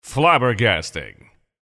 voice_tier5_flabbergasting.mp3